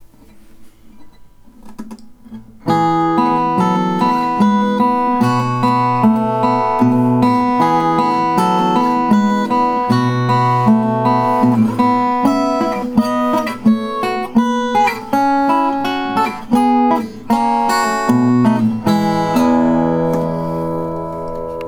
These recordings (at least the ones where I am playing) are amateur recordings.
I use only the built-in microphones on the TASCAM recorder.
These are direct to digital recordings and therefore have the bright all-digital sound.
(These are just meant to try to demonstrate the sound of the guitar!)
B00 in Sitka spruce and plain mango